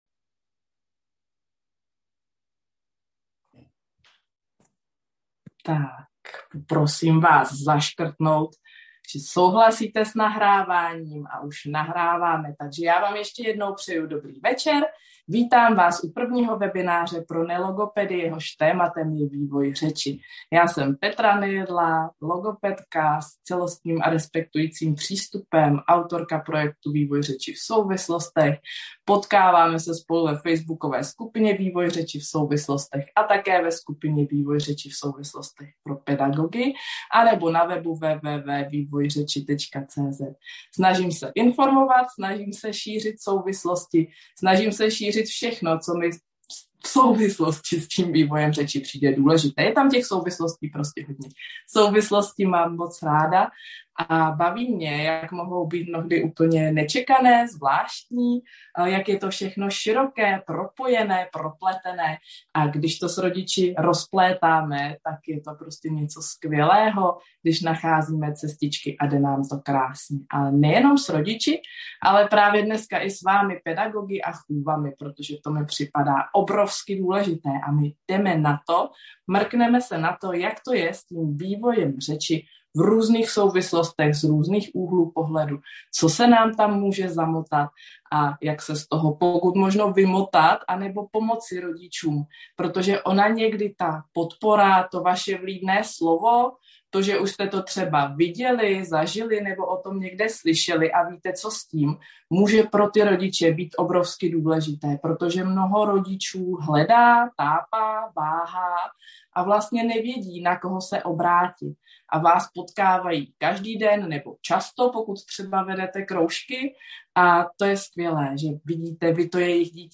Webinář pro nelogopedy